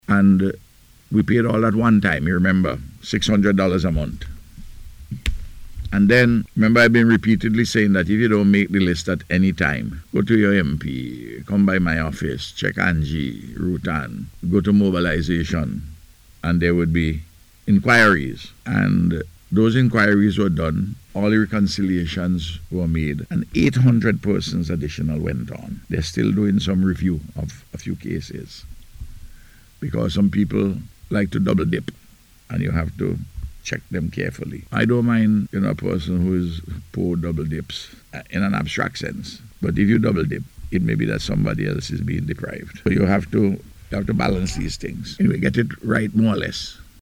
Speaking on NBC Radio on Wednesday, Prime Minister Gonsalves said this money was spent between January and June and was disbursed by the Ministry of National Mobilization.